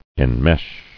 [en·mesh]